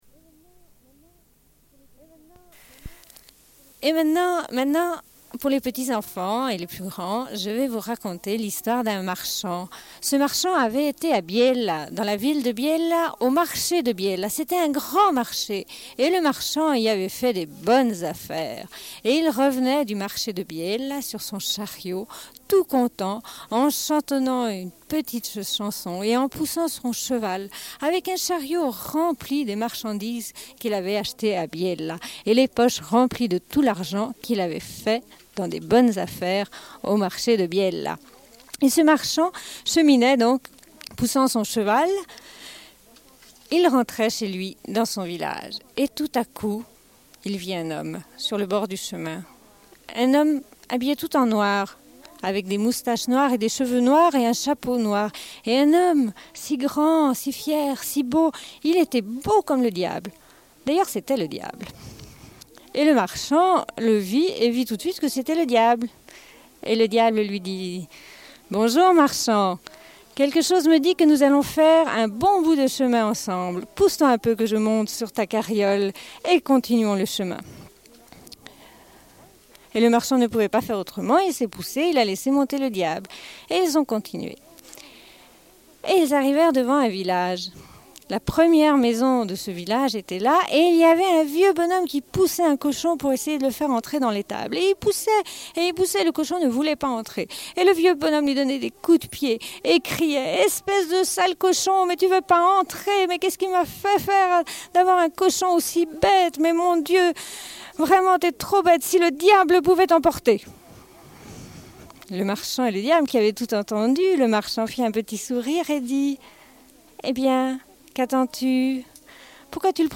Une cassette audio, face B22:25